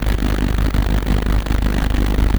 ELECTRICITY_Distorted_Fizzy_loop_mono.wav